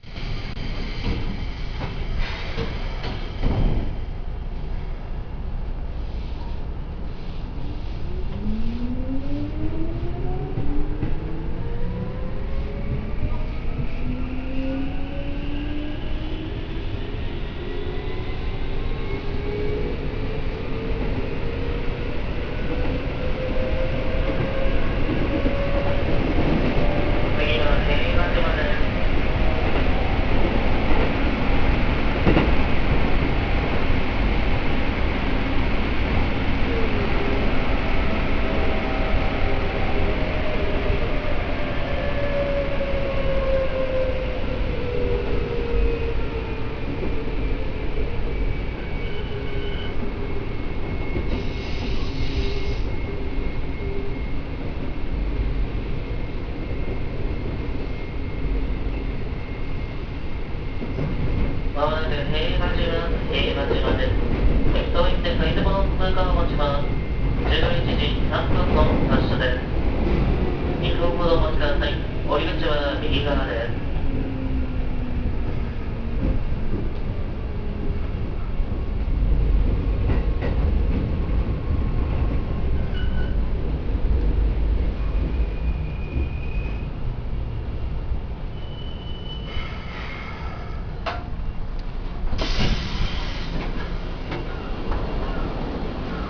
〜車両の音〜
・800形走行音
【京急本線】大森町〜平和島（1分36秒：524KB）
そして、地味にとてもいい音を立ててくれる車両でもあります。